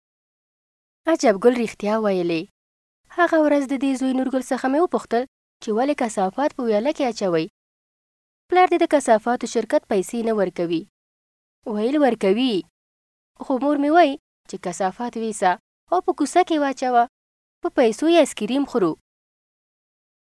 Professional Female Pashto Voice Samples
Our female Pashto voice artists offer a wide range of tones.
FEMALE_PASHTO-6.mp3